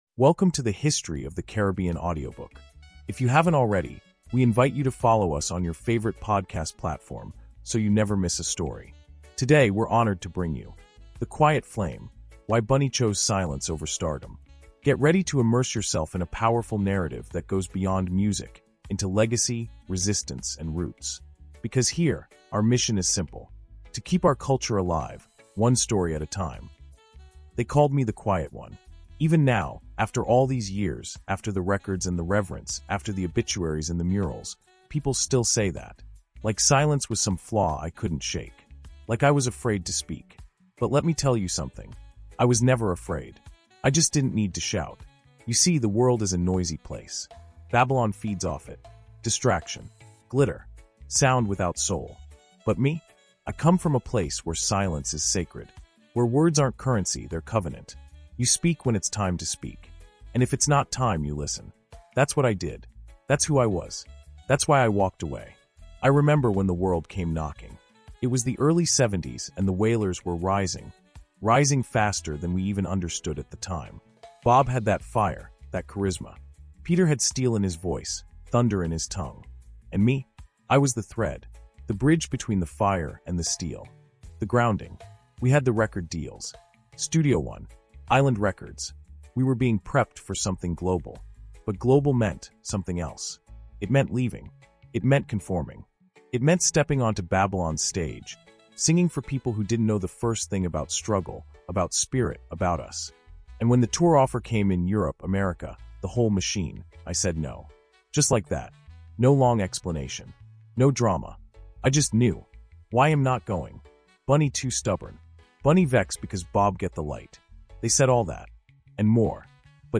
The Quiet Flame dives deep into Bunny’s refusal to tour, his growing discomfort with fame, and his bold decision to live outside Babylon’s stage—choosing spirit over spotlight. Through intimate narration, spiritual context, and cultural reflection, we discover why his silence wasn’t retreat… but rebellion.